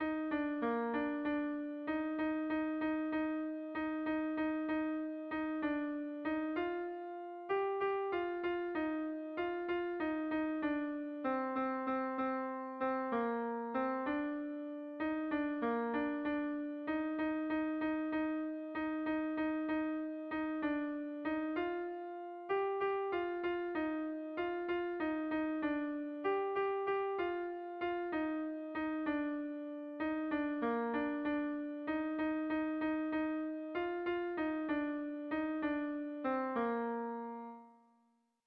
Kontakizunezkoa
Hamarreko handia (hg) / Bost puntuko handia (ip)
A1B1A1B2A2